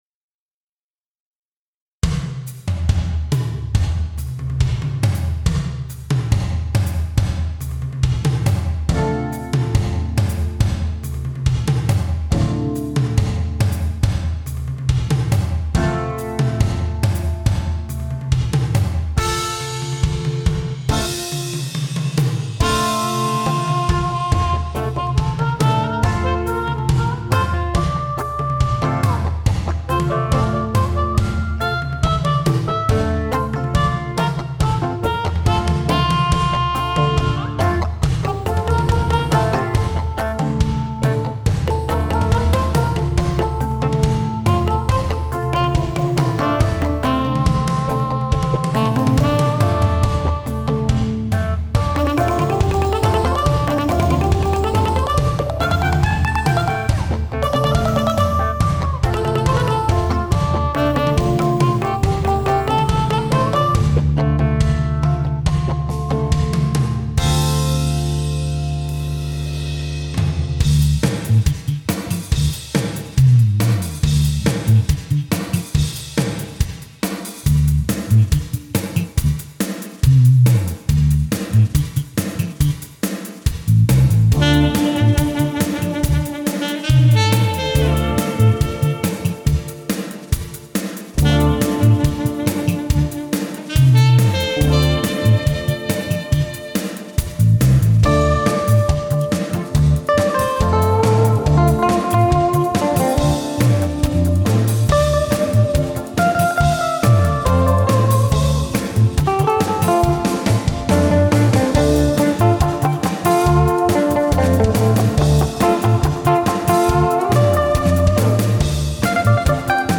JAZZ ROCK / FUSION / GROOVE